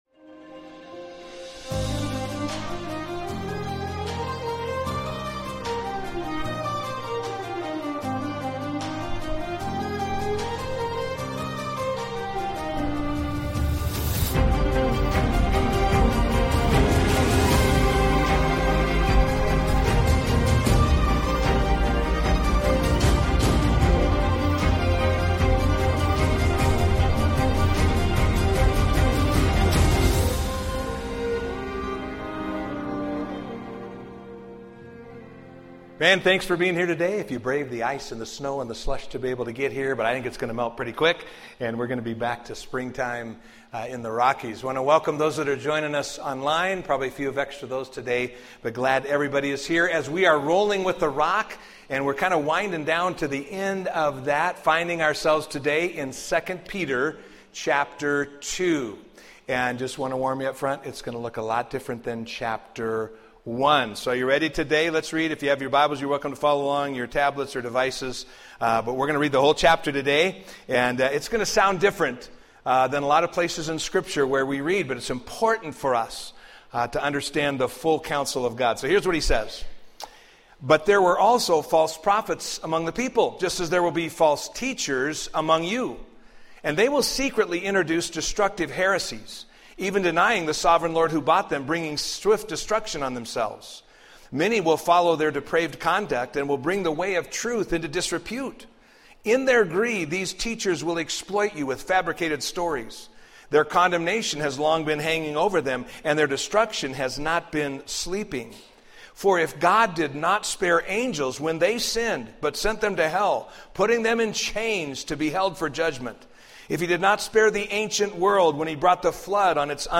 A message from the series "Rolling with the Rock."